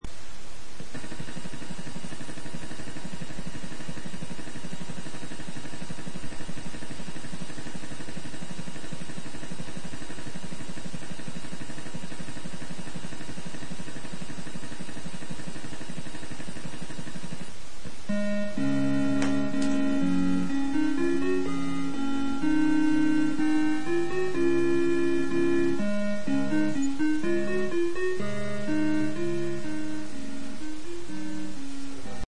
při nahrávání her mi to vydává divný zvuk (kromě toho klasického
nahrávání jak z kazeťáku, tak je tam jak by nějaké zvonivé rušení +
ještě zvuk jak po zapnutí atarka tak pořád po dobu nahravání), máto tak